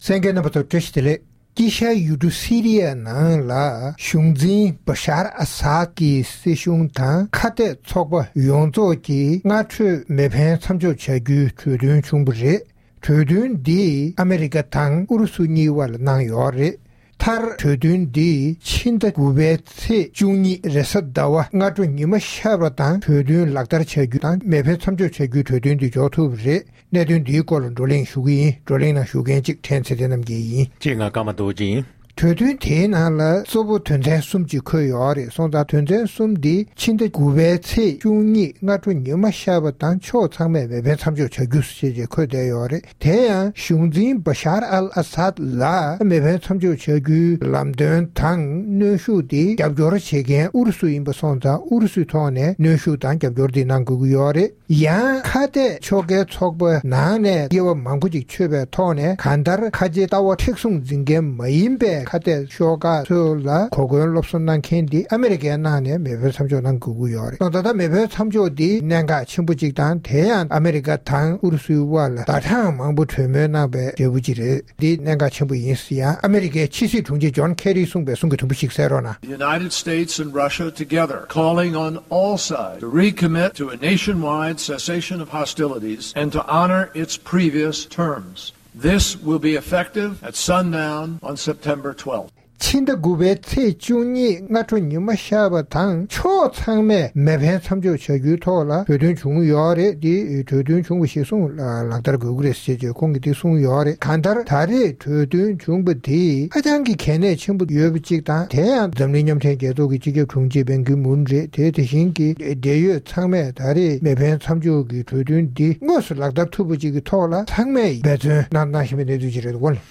༄༅༎ཐེངས་འདིའི་རྩོམ་སྒྲིག་པའི་གླེང་སྟེགས་ཞེས་པའི་ལེ་ཚན་ནང་། AMERICA དང་RUSSIA གཉིས་ཀྱི་ཕྱི་སྲིད་དྲུང་ཆེ་རྣམ་གཉིས་ཀྱིས་གྲོས་མོལ་རིམ་པ་བརྒྱུད་དཀྱིལ་ཤར་ཡུལ་གྲུ་Syria ནང་དམག་འཁྲུག་མཚམས་འཇོག་དང་།་མི་མང་ལ་བཟའ་བཅའ་སྐྱེལ་འདྲེན་བྱ་རྒྱུ་བཅས་ཀྱི་ཐག་གཅོད་གསལ་བསྒྲགས་གནང་བ་དང་སྦྲེལ་ལག་བསྟར་གནང་བཞིན་པ་སོགས་ཀྱི་སྐོར་རྩོམ་སྒྲིག་འགན་འཛིན་རྣམ་པས་བགྲོ་གླེང་གནང་བ་ཞིག་གསན་རོགས་གནང་།།